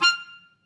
DCClar_stac_F5_v3_rr2_sum.wav